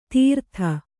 ♪ tīrtha